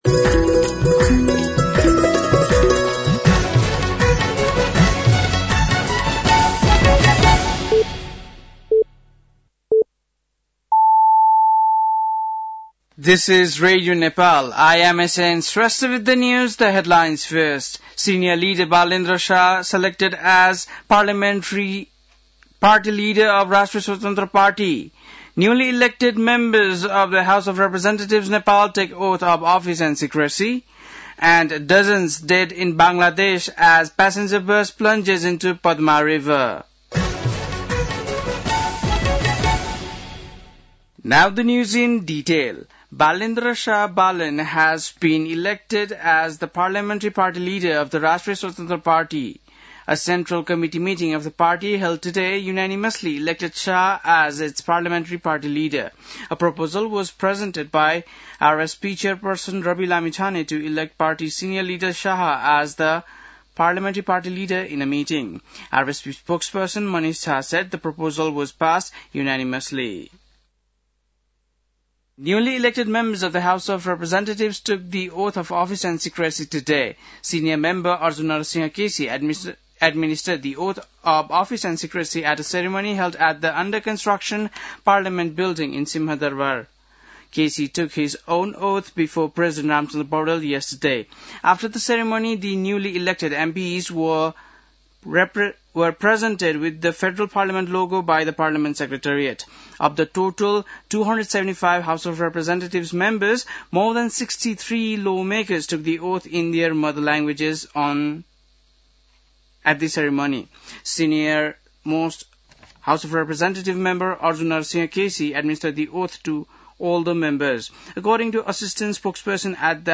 बेलुकी ८ बजेको अङ्ग्रेजी समाचार : १२ चैत , २०८२